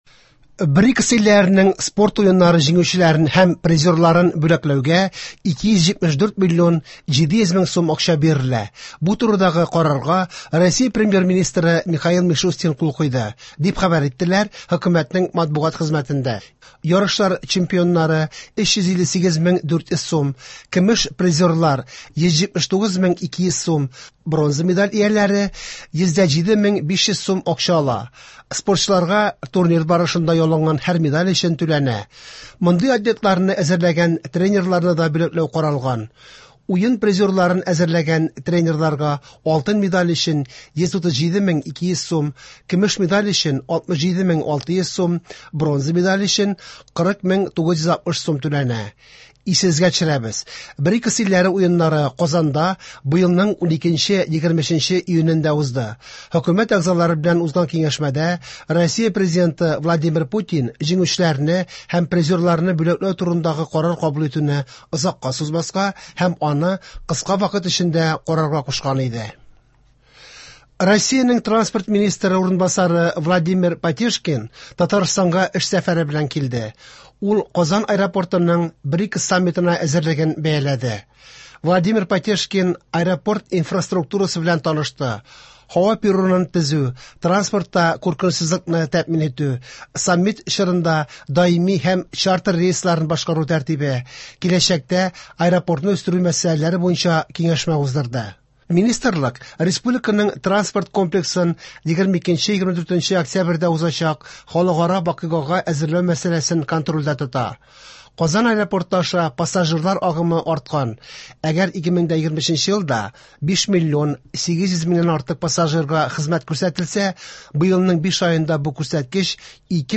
Яңалыклар (15.07.24)